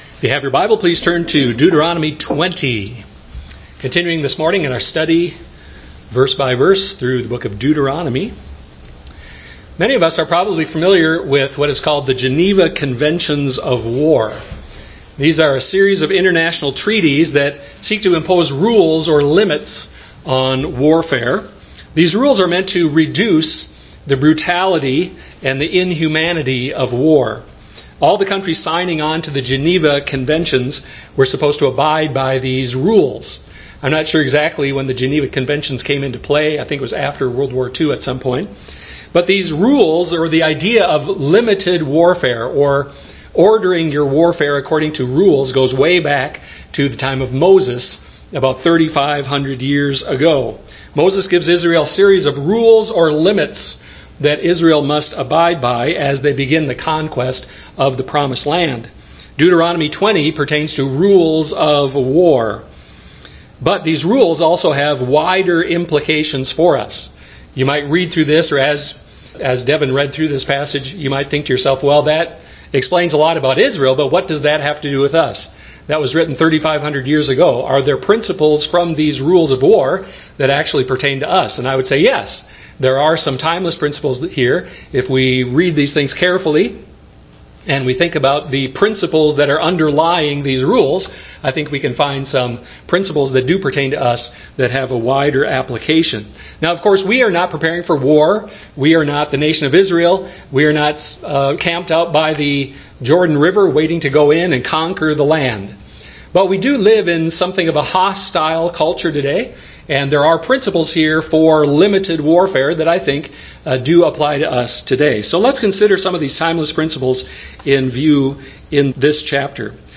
Deuteronomy 20 Service Type: Sunday morning worship service Many of us have probably heard of the Geneva Conventions of War.